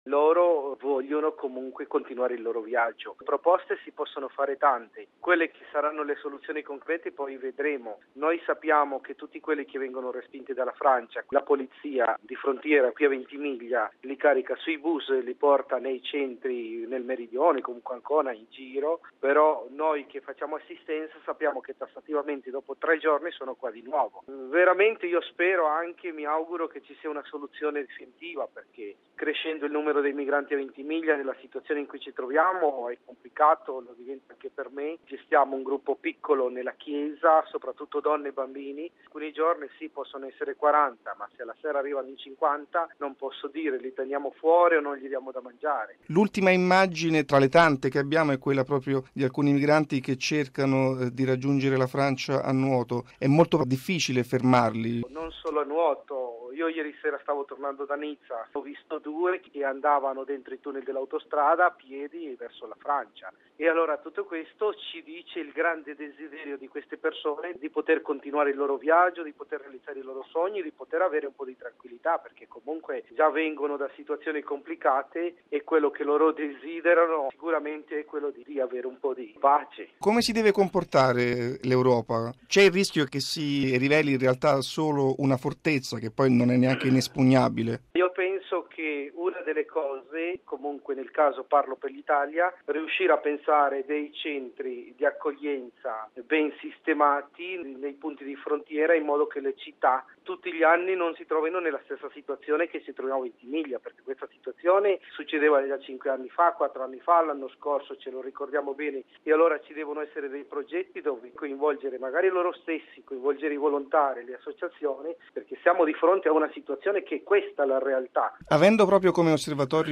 al microfono